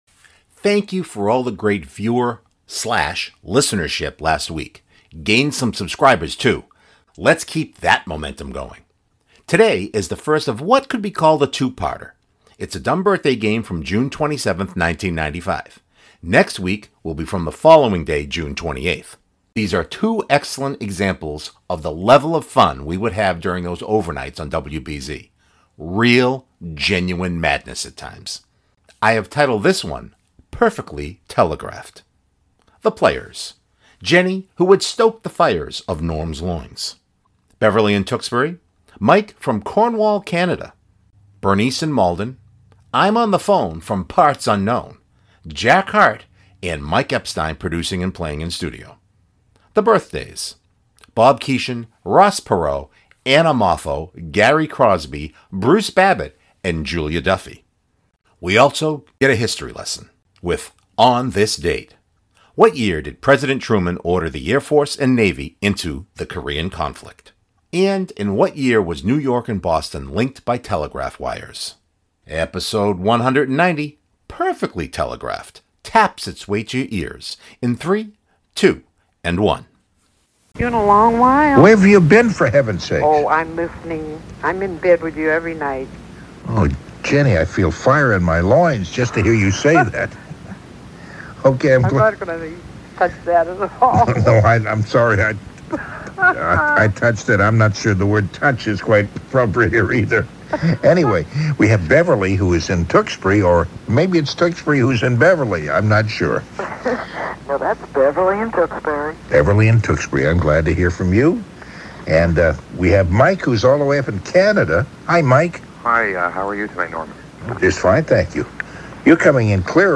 These are two excellent examples of the level of fun we would have during those overnights on WBZ.